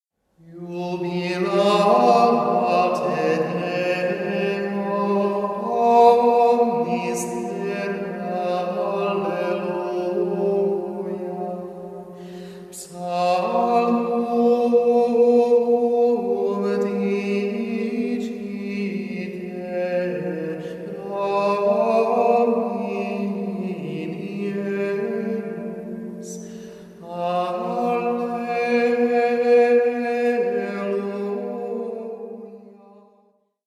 Il canto gregoriano, espressione più antica della Chiesa di Roma, presenta un vastissimo repertorio di inni, salmodie e sequenze.
L’introito Iubilate Deo, che esalta la gloria di Dio e delle sue opere, è tratto dal Salmo 65 e viene cantato nella Terza domenica di Pasqua. Una melodia semplice e, allo stesso tempo, nobile e grandiosa.